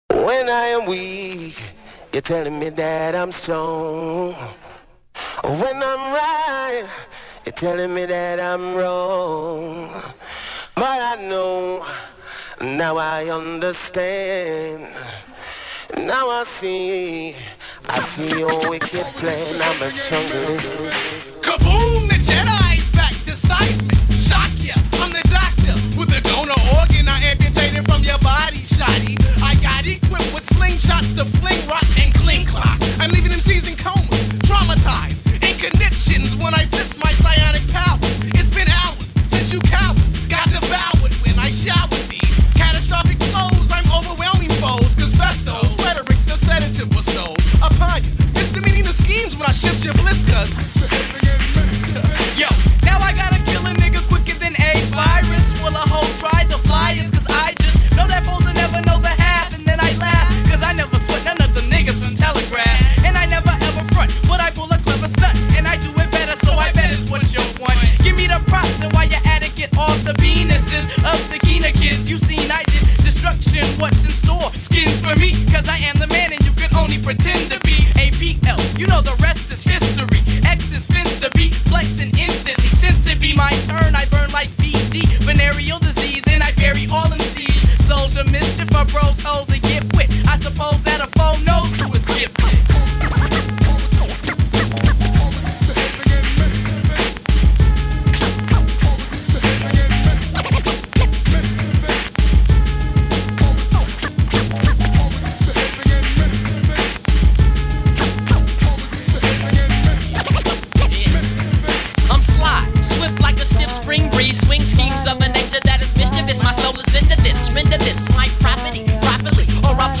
Ragga Jungle,Hip Hop Mix DOPE MIX!!!!!